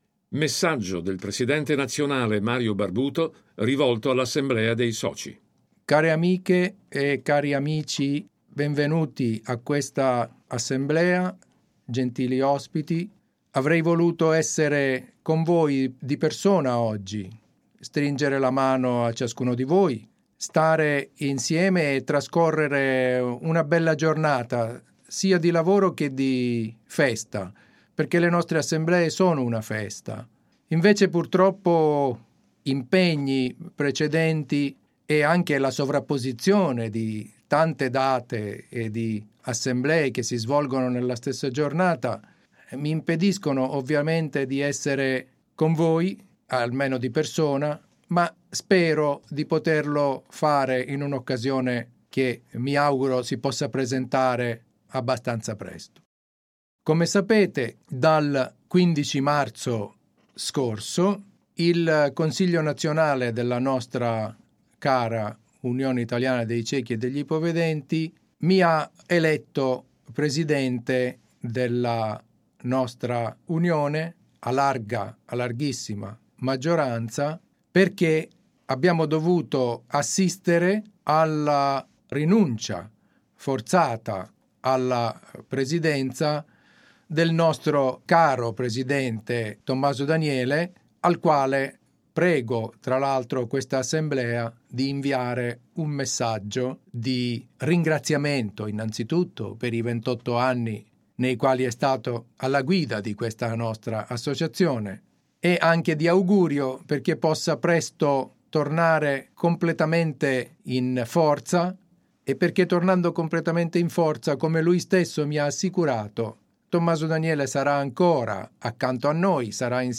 rivolto a tutti i soci dell'Unione in occasione dell'Assemblea Sezionale.